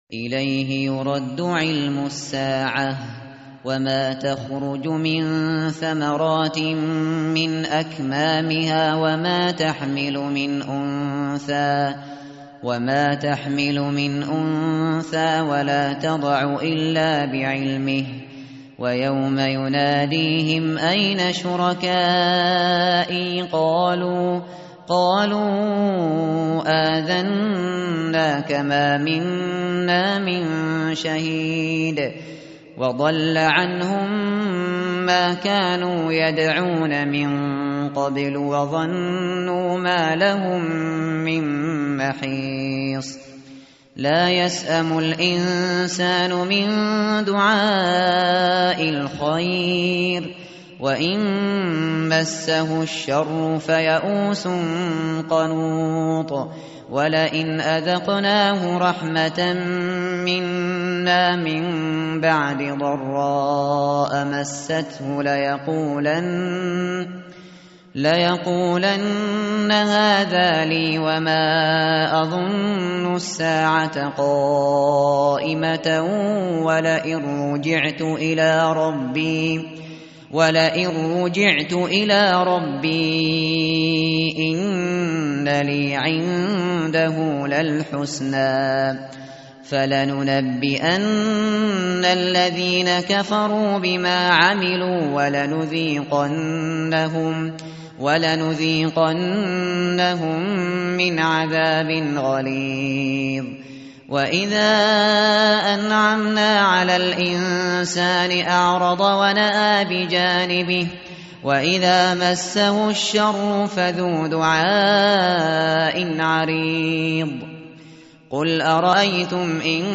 متن قرآن همراه باتلاوت قرآن و ترجمه
tartil_shateri_page_482.mp3